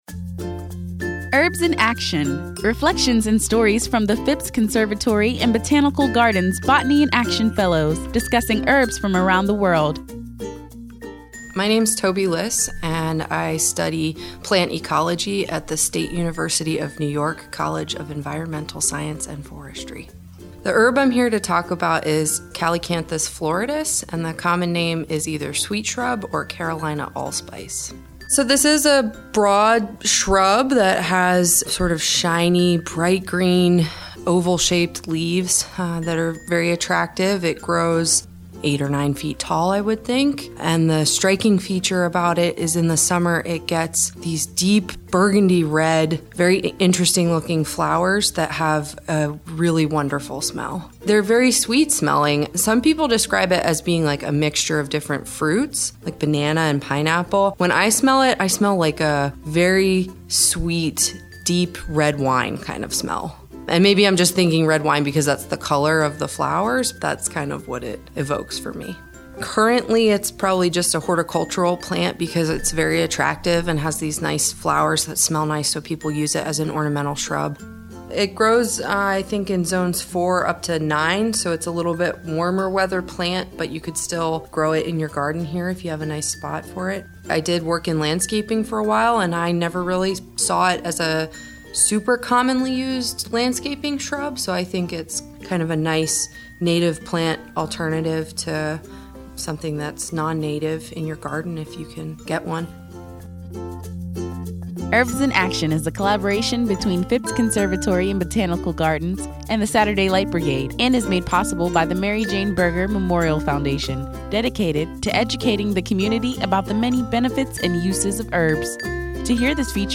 Listen as they share their knowledge of and experiences with these herbs as botanists of the world.